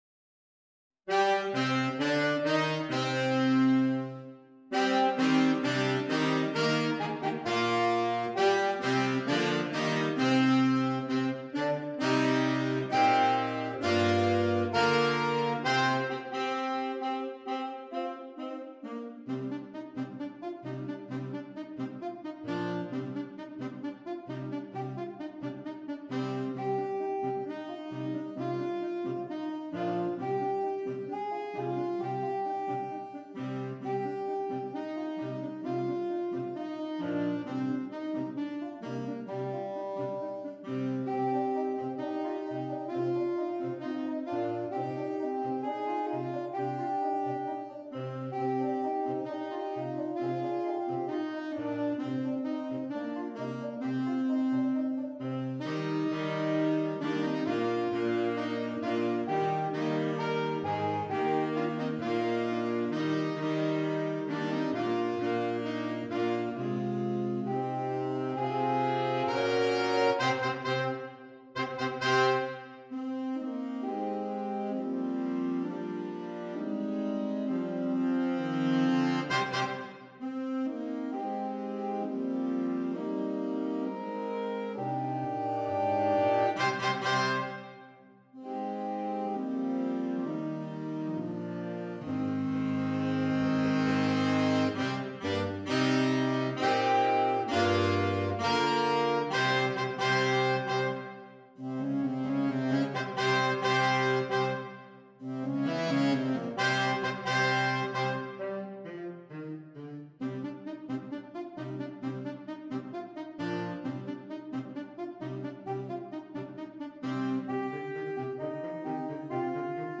Saxophone Quartet (AATB)
The music is always moving forward-advancing.